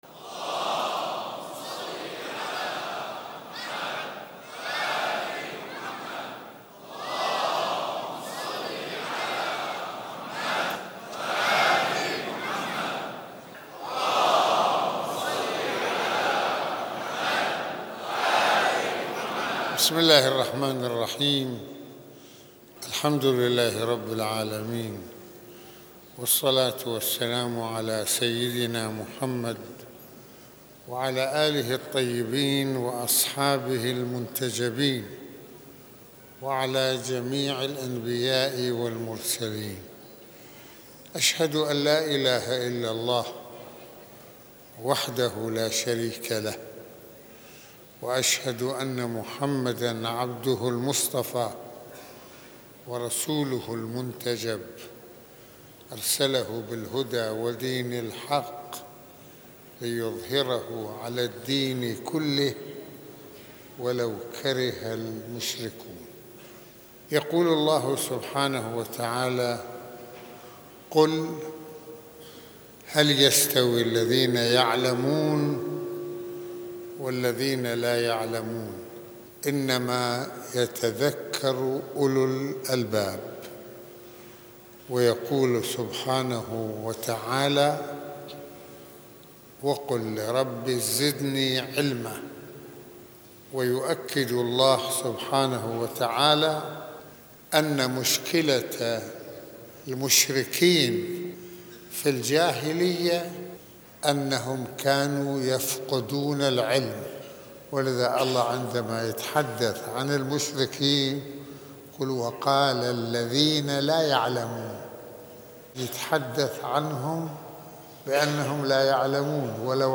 - المناسبة : خطبة الجمعة المكان : مسجد الإمامين الحسنين (ع) المدة : 39د | 29ث المواضيع : الإمام عليّ(ع) أنموذجٌ رساليٌّ خالد - فضيلة العلم - عليّ(ع) وريث رسول الله(ص) - منـزلة عليّ(ع) عند رسول الله(ص) - وصايا الإمام.